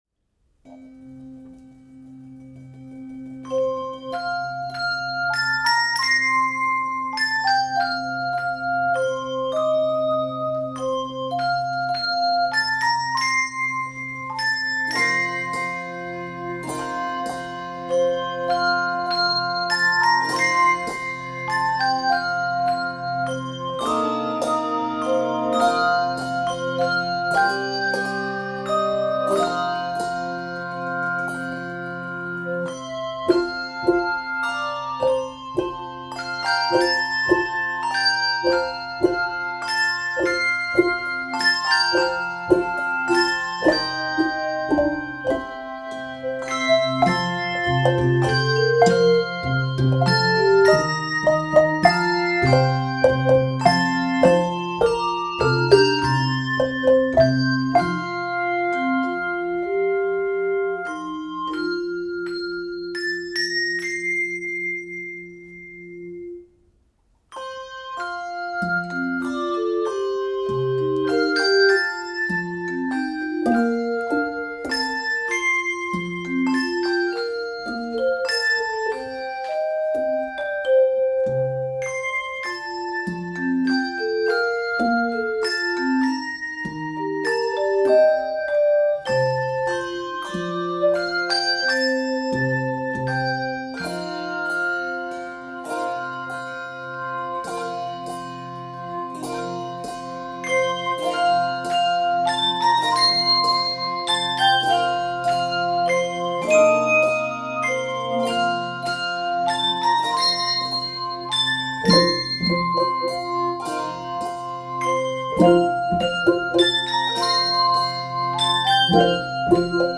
enjoys a Celtic treatment